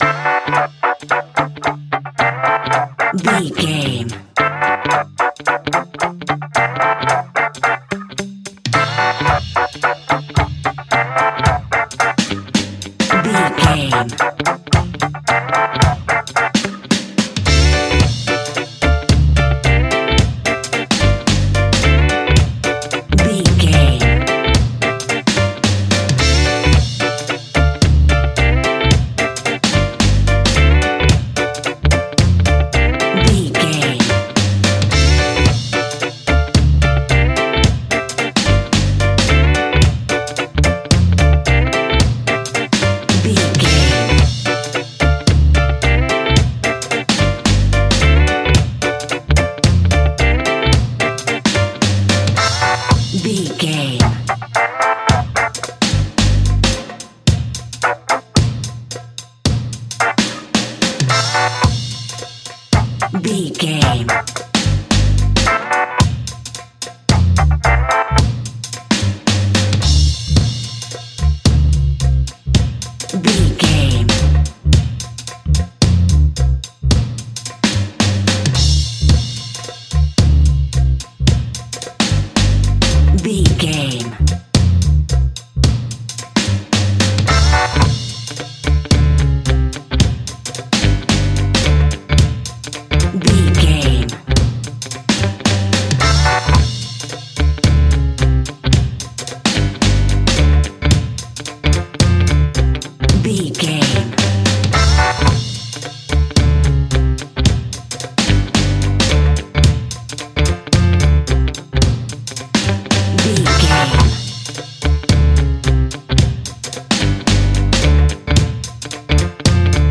The hot summer reggae sounds!
Aeolian/Minor
dub
reggae instrumentals
laid back
chilled
off beat
drums
skank guitar
hammond organ
percussion
horns